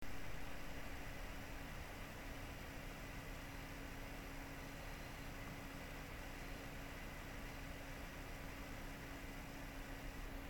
Aušinimo ir triukšmo rezultatai
NH-L9a-AM5 (L.N.A. 1800 RPM @31,8 dBA)